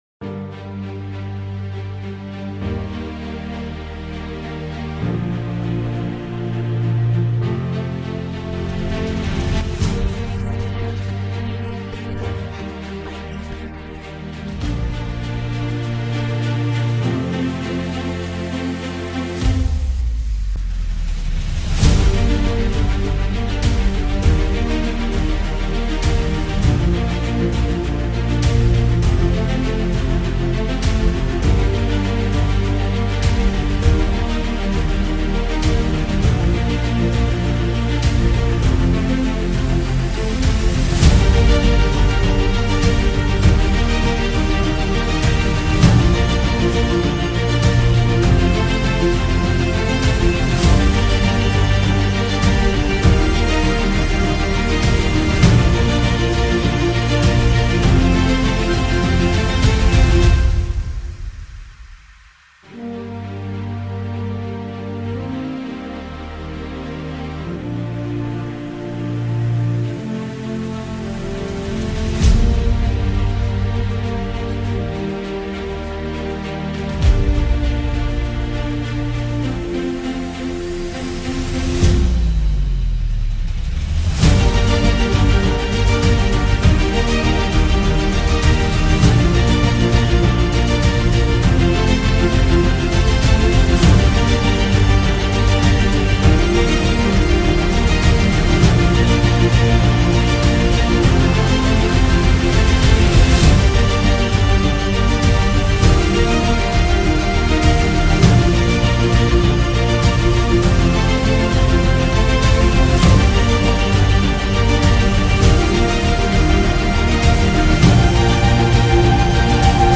恢弘